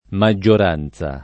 maggioranza [ ma JJ or # n Z a ] s. f.